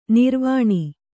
♪ nirvāṇi